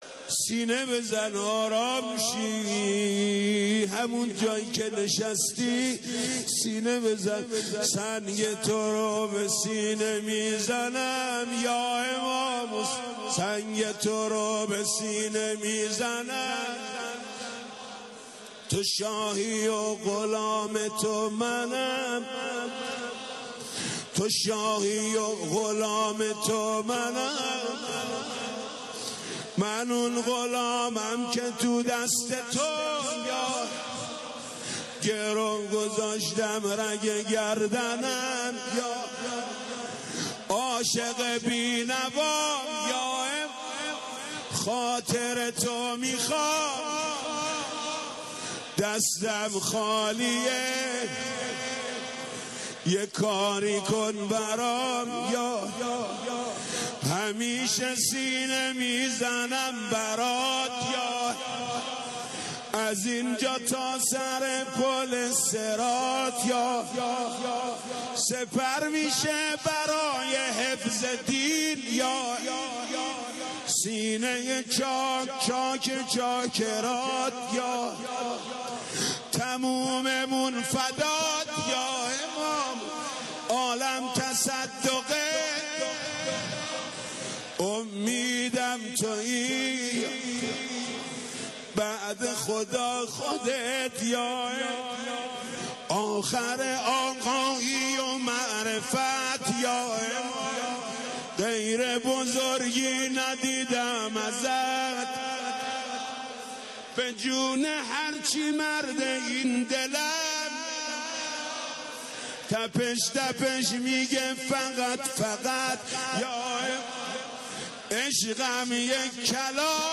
حاج محمود کریمی/مراسم ماه مبارک رمضان96
بخش دوم/روضه
بخش ششم/سینه زنی